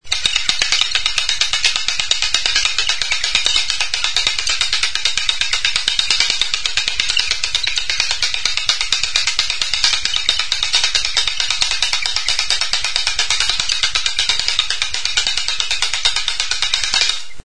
Idiófonos -> Golpeados -> Indirectamente
Kirtena esku tartean bi zentzuetara jiratuz, trabeska sartutako makilak alboetako hortzen kontra jotzerakoan hotsa ematen du.